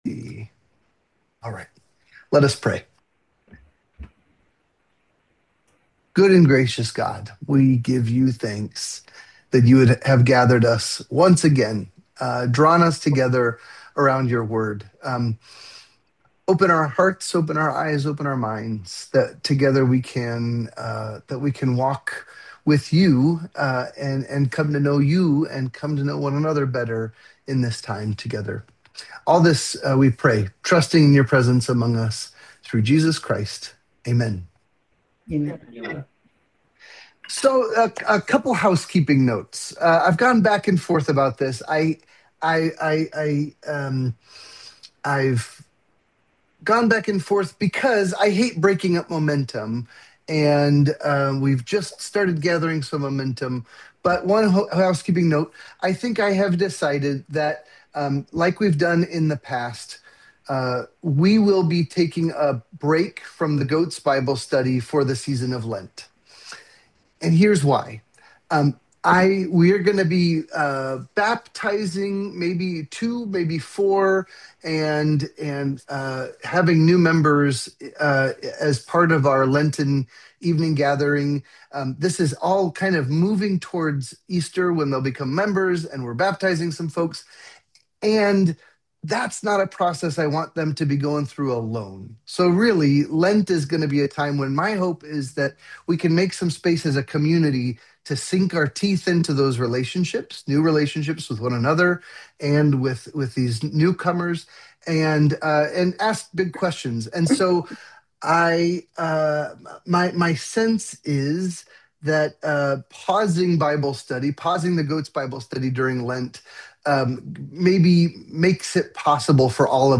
The goats wade into some uncomfortable territory in the start of Chapter 5 after a lively conversation about the early Christian community. What does it mean to hold all things in common? What happens when something is withheld?